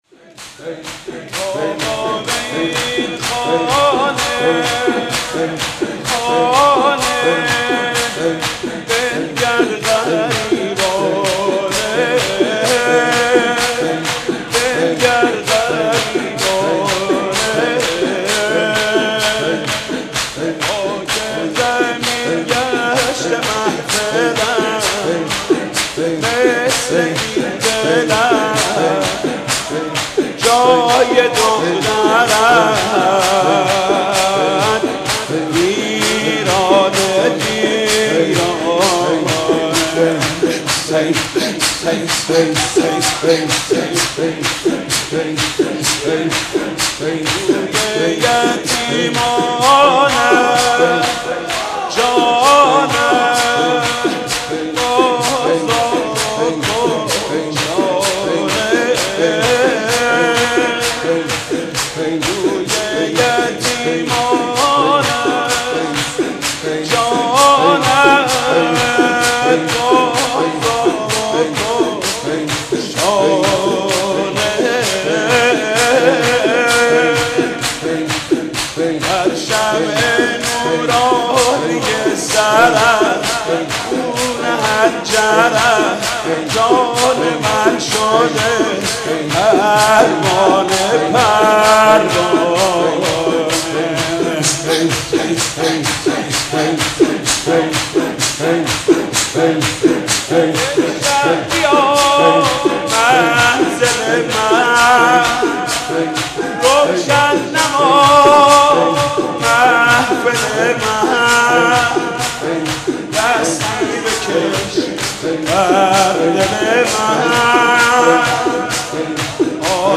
«فاطمیه 1389» شور: بابا به این خانه بنگر غریبانه